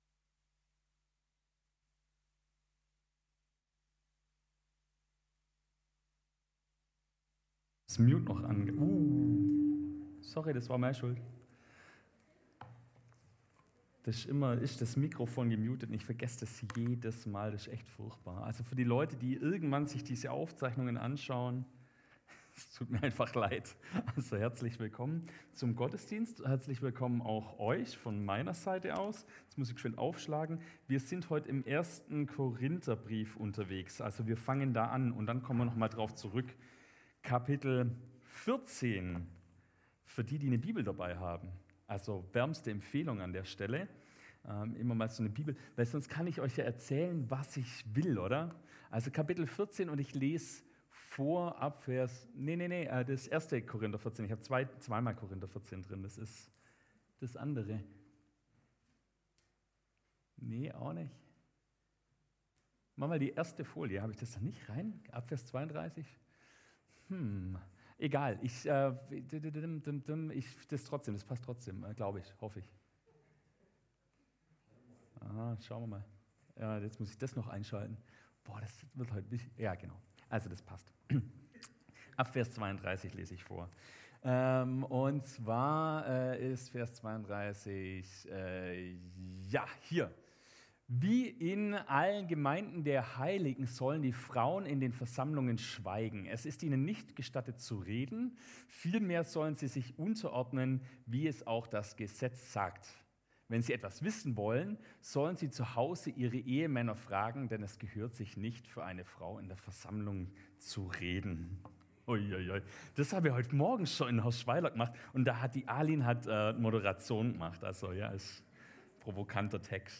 Gottesdienst am 29.10.2023 ~ Liebenzeller Gemeinschaft Schopfloch Podcast